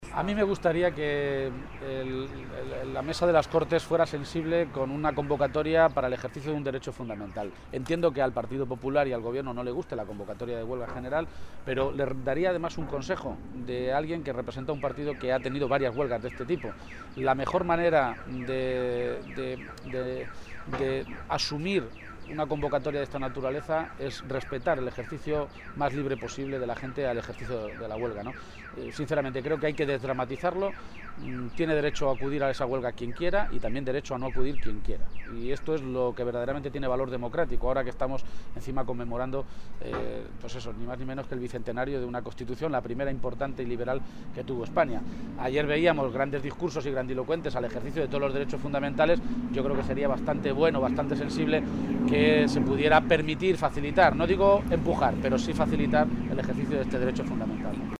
Emiliano García-Page, Secretario General del PSOE de Castilla-La mancha
García-Page hacía este anuncio en declaraciones a los medios de comunicación esta mañana, en Toledo, y reiteraba que “las medidas que se están adoptando ahora, como las del pago a los proveedores por parte de los ayuntamientos, son medidas que están parcheando la situación, pero la angustia y la urgencia del mañana que nos provoca la crisis no pueden hacernos perder la perspectiva y que los árboles no nos dejen ver el bosque: hay que pensar entre todos cómo hacemos sostenibles en el medio plazo la Educación, la Sanidad y los servicios sociales”.
Cortes de audio de la rueda de prensa